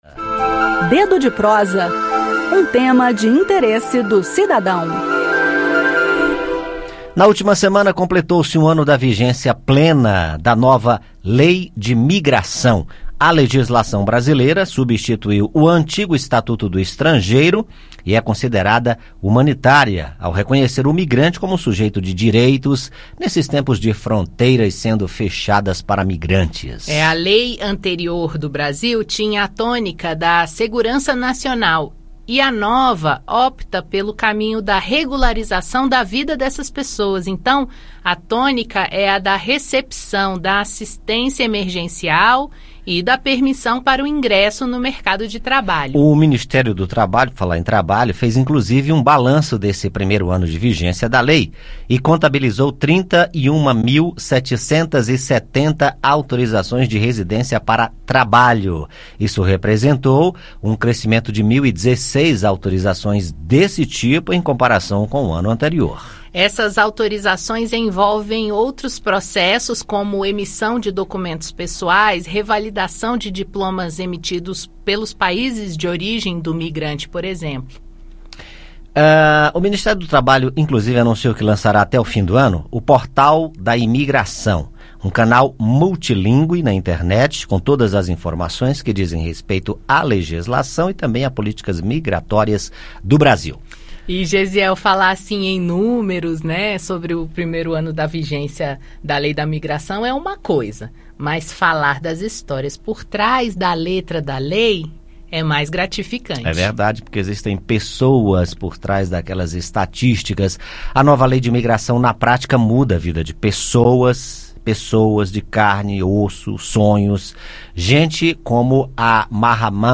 Esse é o assunto do bate papo do Dedo de Prosa desta terça-feira (27).